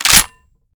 Decay/sound/weapons/arccw_ud/m1014/breechclose.ogg at main
breechclose.ogg